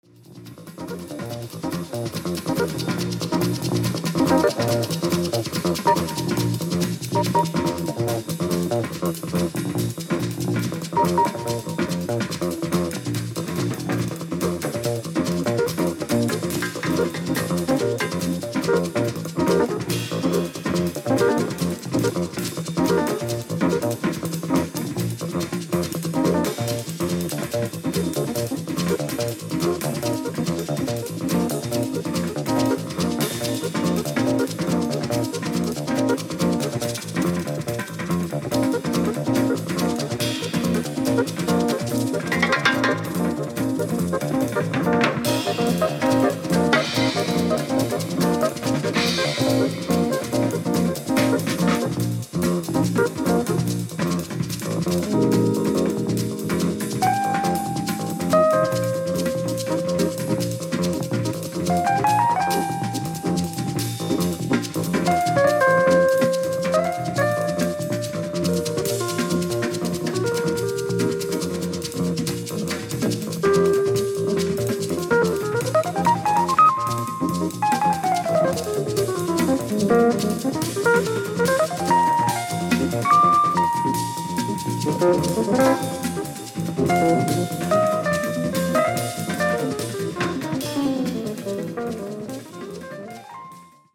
Drums
Electric Bass
Percussion
Piano, Electric Piano